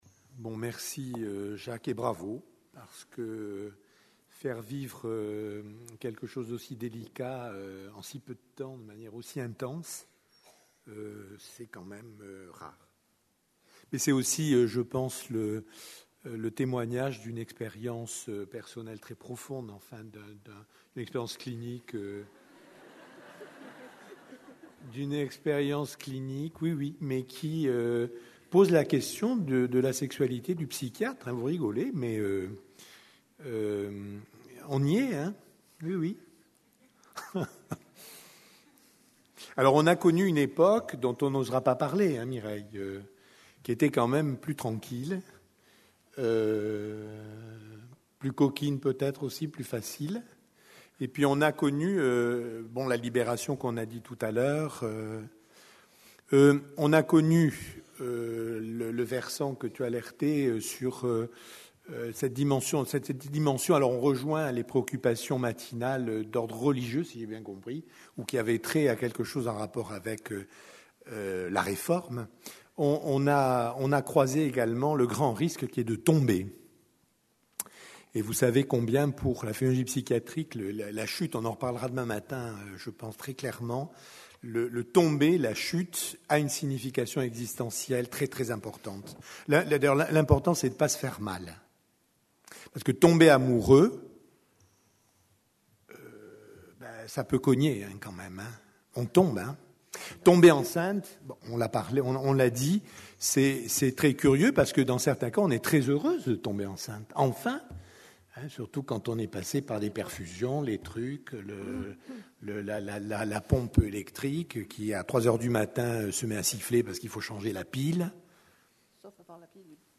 CNIPsy 2010 Marseille - De la psychiatrie à la sexologie. Débat.